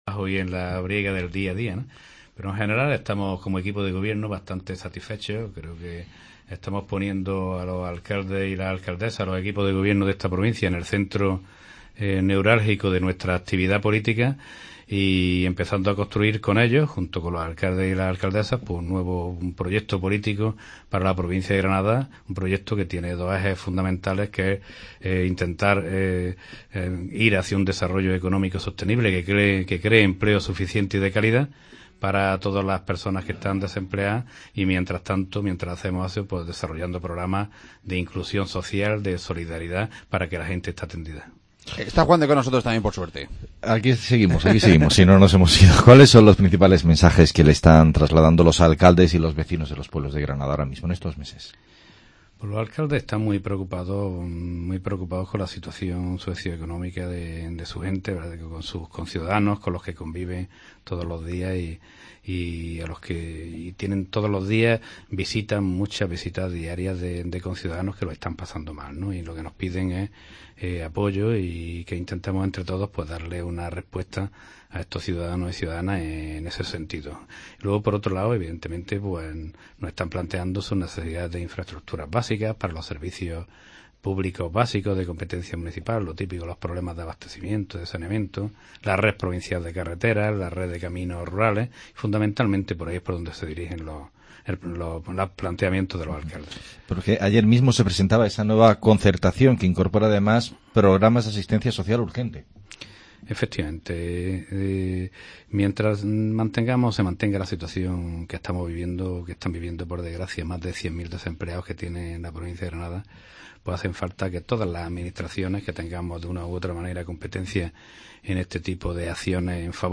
Entrevista a José Entrena, Presidente de Diputación